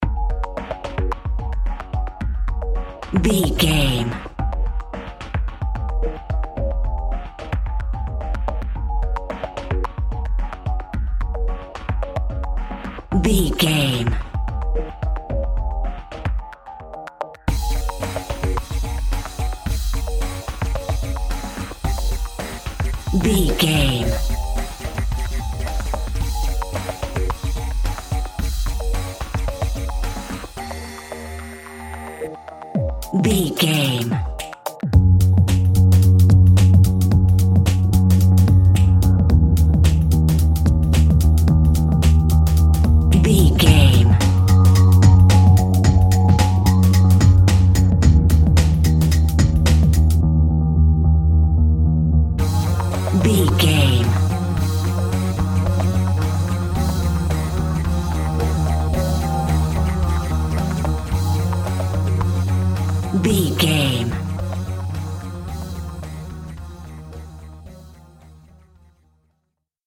Aeolian/Minor
E♭
electronic
new age
techno
trance
drone
synth lead
synth bass